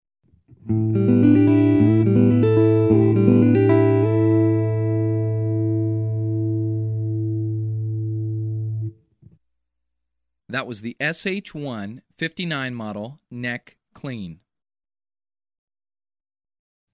Généralement, ils se caractérisent par un son chaud avec niveau de sortie élevé qu’on apprécie souvent avec une distorsion.
SH1 position manche : clean
SH1Neck-Clean1.mp3